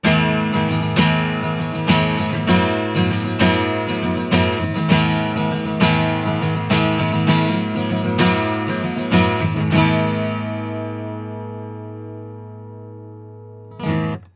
音質は、高音に味付がされており、
抜けの良いサウンドになっています。
実際の録り音
アコースティック・ギター
SE2200-アコギ.wav